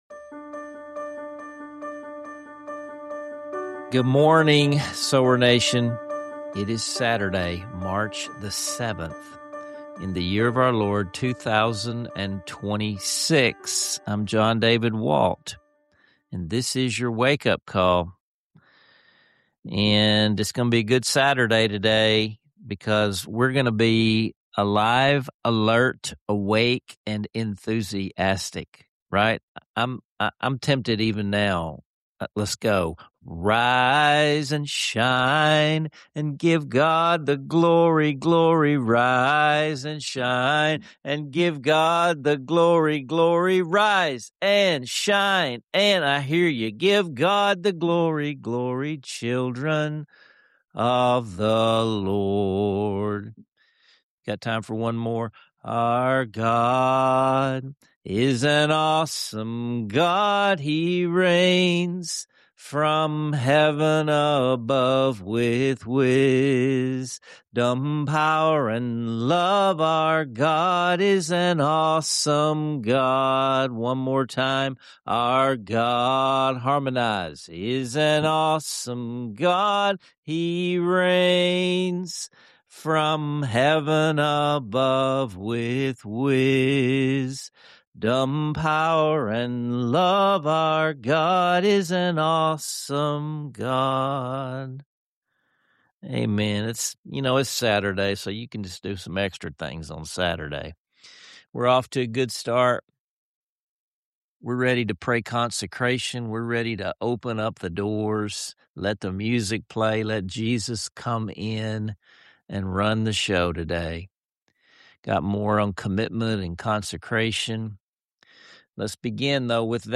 Tune in, sing along, and step out encouraged to follow, not just strive.